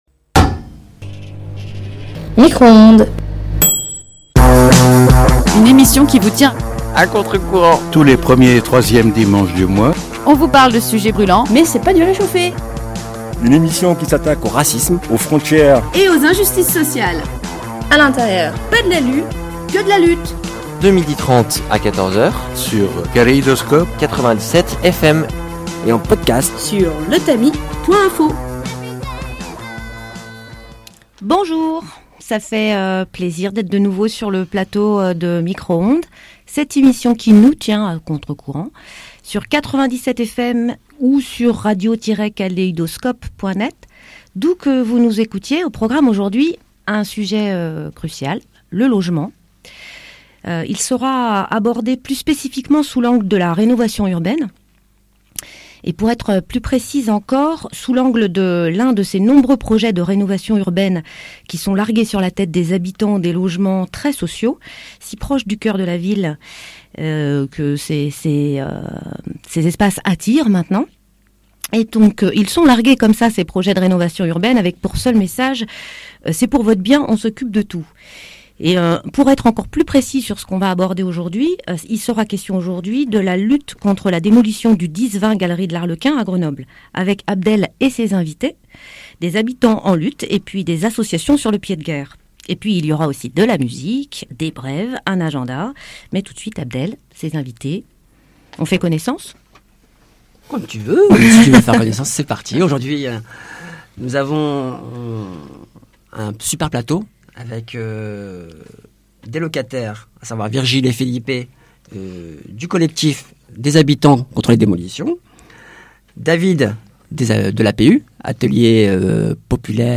Micro-Ondes est une émission de radio diffusée tous les premiers dimanches du mois de 12h30 à 14h, sur Radio Kaléidoscope (97FM).
Au programme de cette émission, une émission spéciale autour de la lutte contre la démolition du 10-20 de la galerie de l'Arlequin à la Vileneuve et les dynamiques de gentrifications dans les villes avec 4 invités autour du plateau :